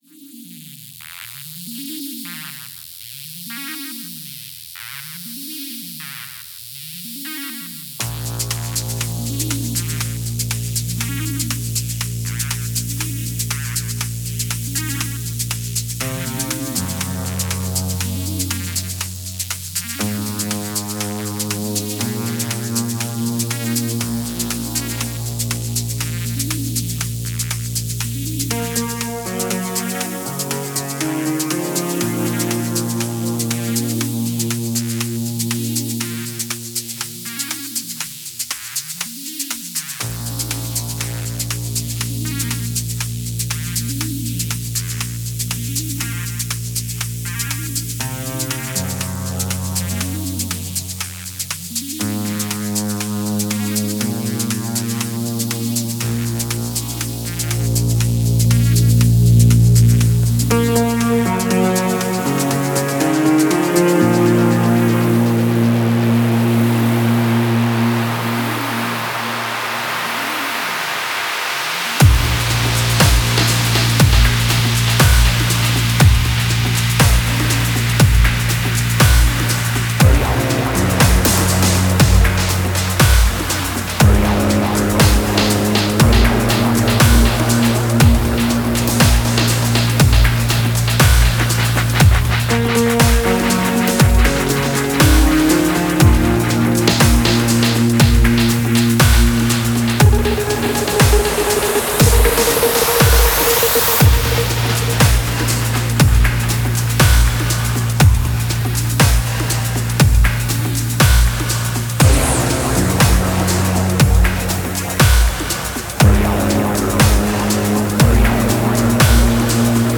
Genre: Electro.